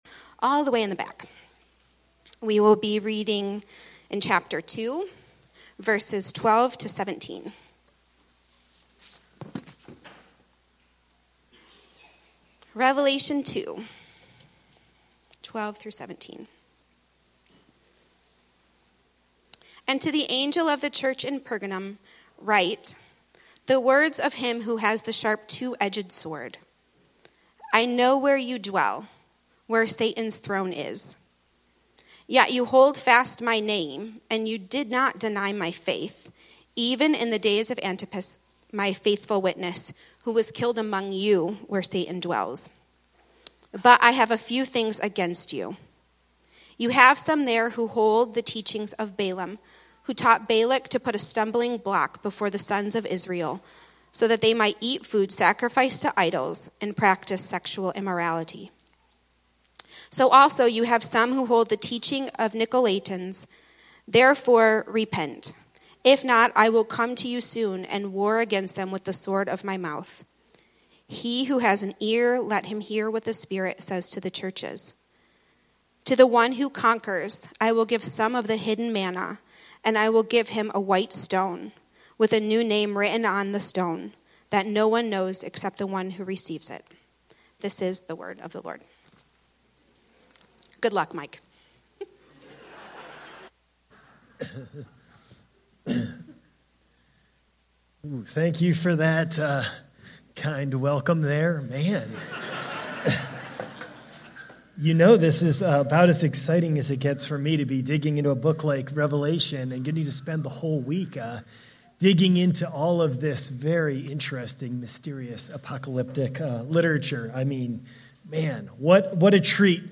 Sermons by Redemption City Church